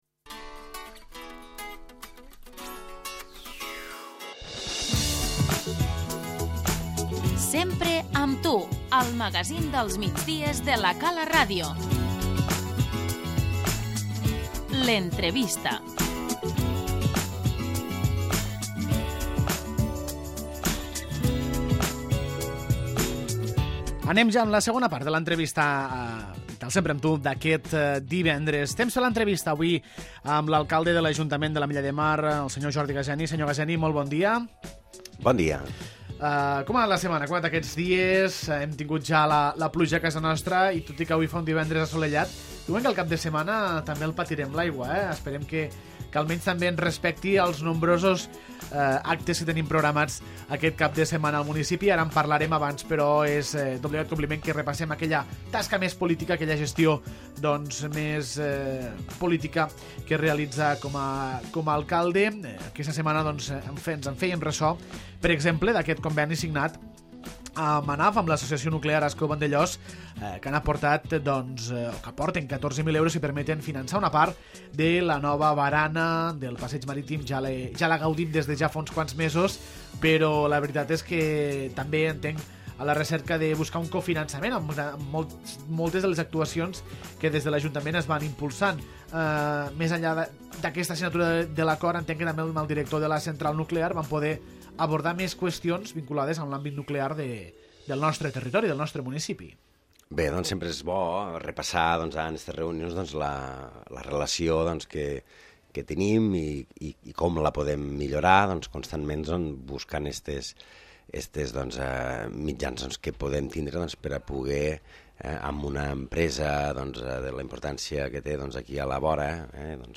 L'entrevista - Jordi Gaseni, alcalde
A l'Entrevista ens acompanya l'alcalde de l'Ajuntament de l'Ametlla de Mar, Jordi Gaseni, amb qui repassem tota l'actualitat del municipi, centrada especialment amb la pròxima aprovació dels comptes per 2017, les diferents actuacions que s'estan realitzant i l'agenda del cap de setmana.